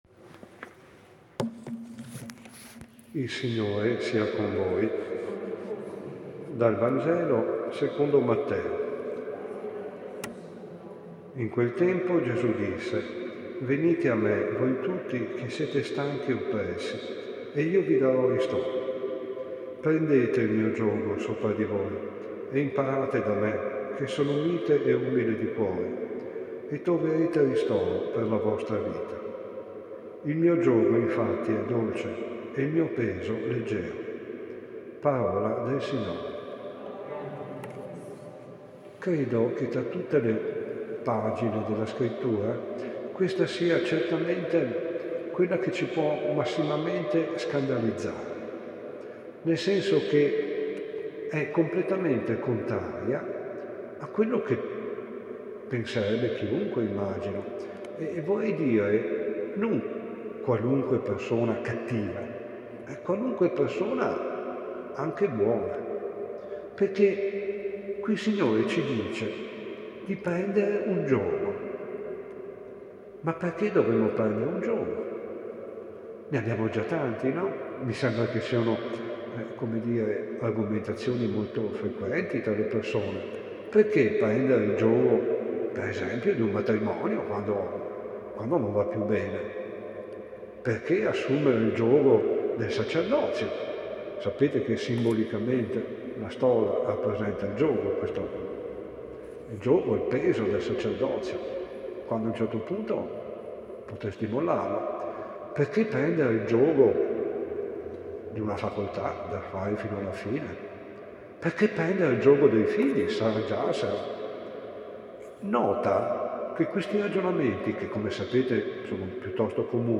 Omelie giornaliere old - CappellaDellaSapienza